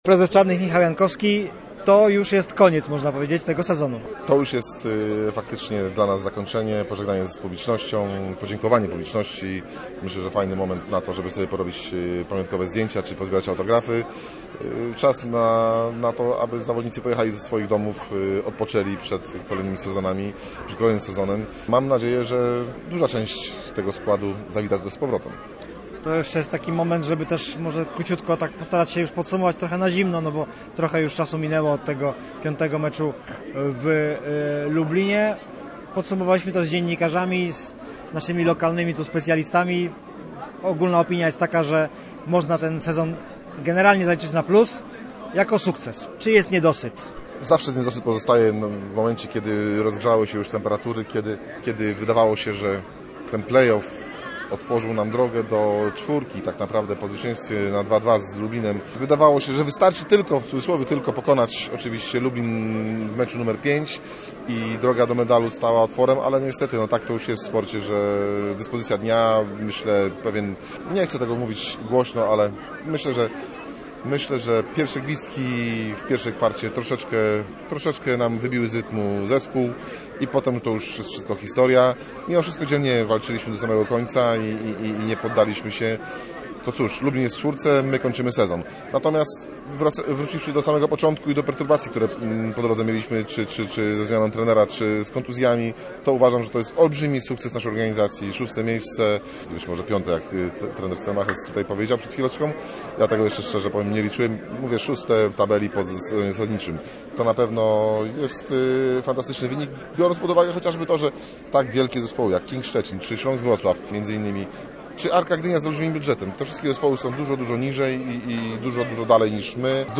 Koszykarze Energa Icon Sea Czarnych Słupsk zakończyli sezon w Orlen Basket Lidze. Tradycyjnie pożegnali się z kibicami na bulwarach nad Słupią.
Posłuchaj relacji z zakończenia sezonu koszykarskiego w Słupsku: https